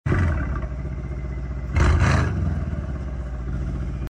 Basically An AWD V8 C63… Sound Effects Free Download